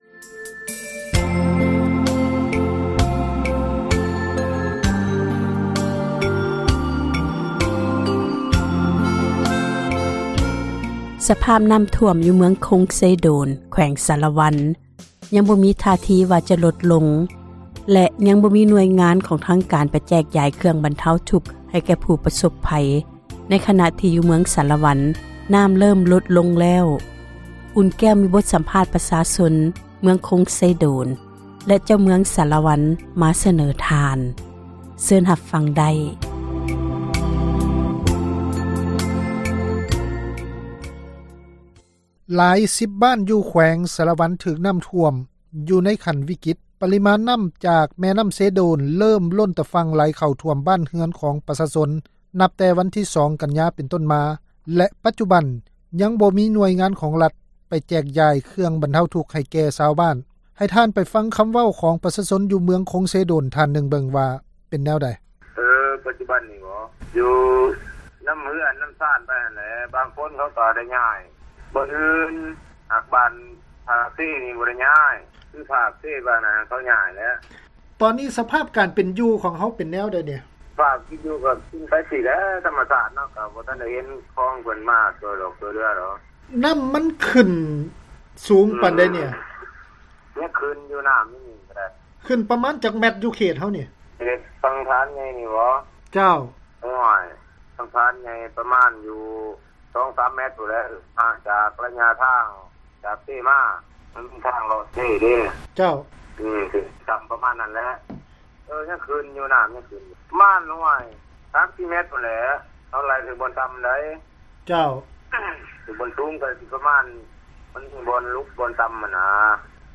ສໍາພາດຜູ້ປະສົພພັຍ ນໍ້າຖ້ວມ